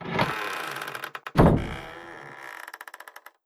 SFX_Toilet_Open.wav